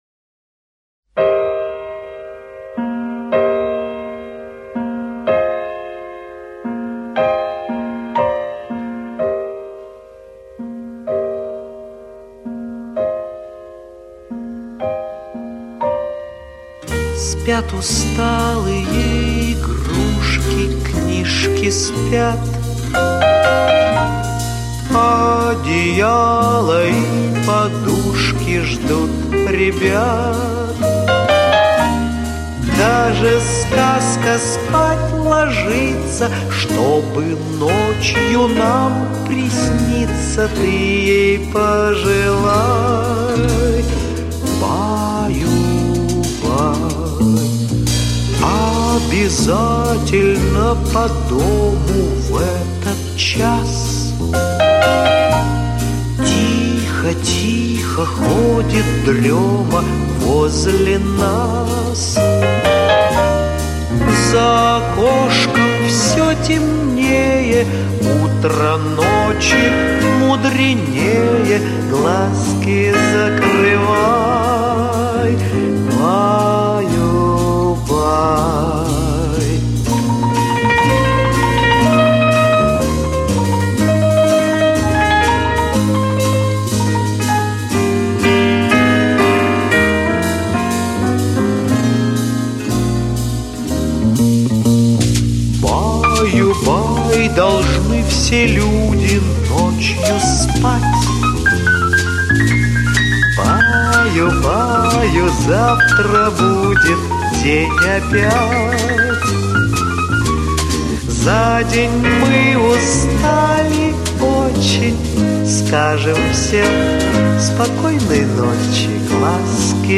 Главная » Колыбельные песни